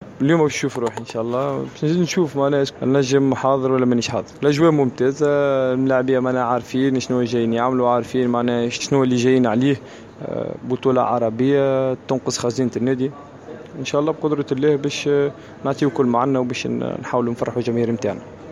عقد مدرب النجم الساحلي روجي لومار ندوة صحفية اليوم الإربعاء 17 أفريل 2019 صحبة اللاعب عمار الجمل للحديث حول مباراة نهائي كأس زايد للأبطال أمام الهلال السعودي المبرمجة يوم الخميس 18 أفريل 2019 في ملعب هزاع بن زايد في مدينة العين.